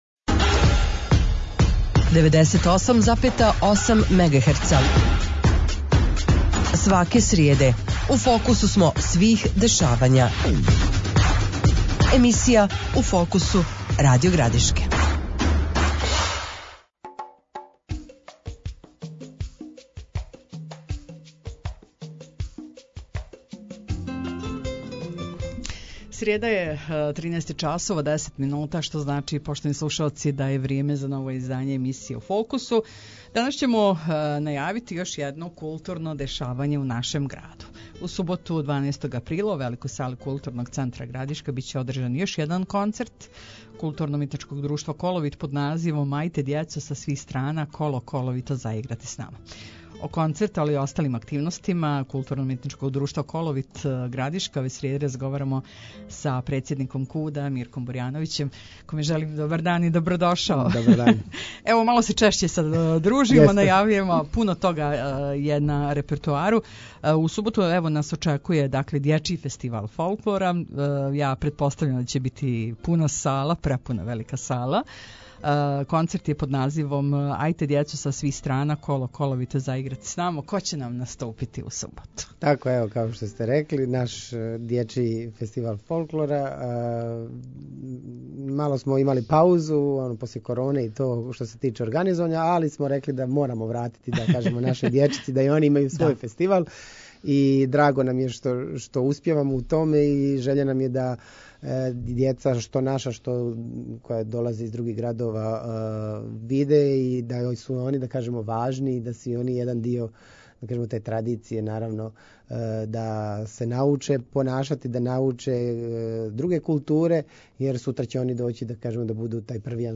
Гостујући у емисији „У фокусу“ Радио Градишке